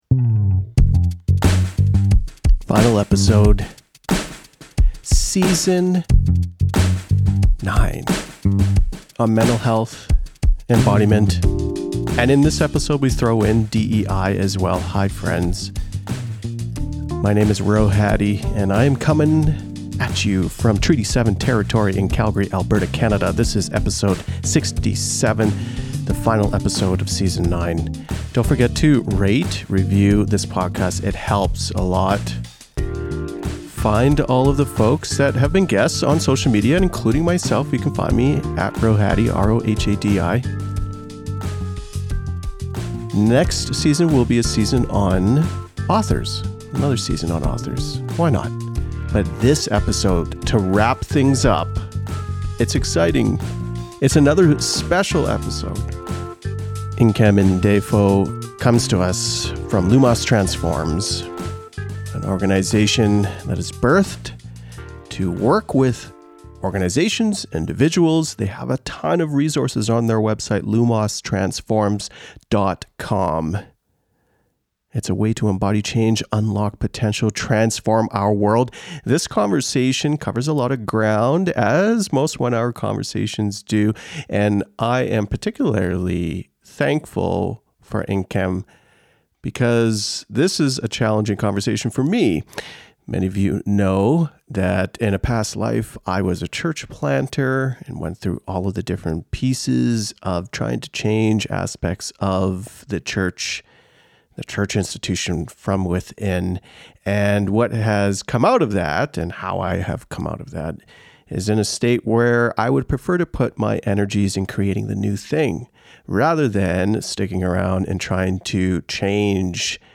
The final Episode of Season 9, a season on mental health, embodiment, and in this episode we throw in change and some DEI (diversity equity and inclusion) as well. Recorded on Treaty 7 territory in Calgary, Alberta, Canada.